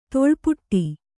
♪ toḷpuṭṭi